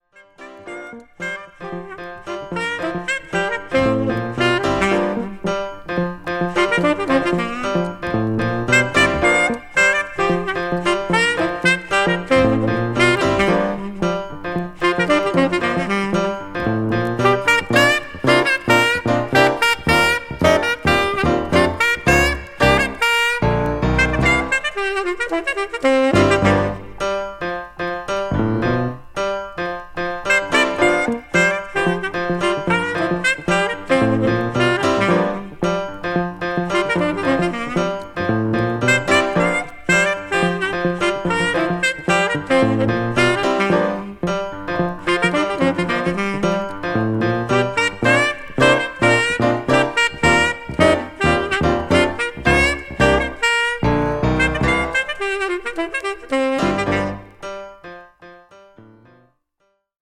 ホーム ｜ JAZZ / JAZZ FUNK / FUSION > JAZZ